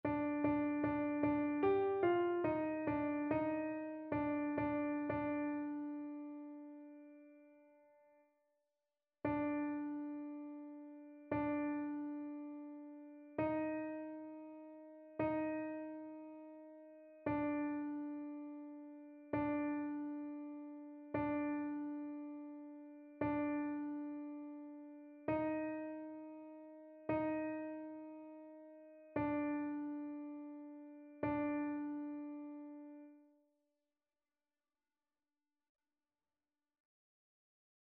annee-a-temps-ordinaire-25e-dimanche-psaume-144-alto.mp3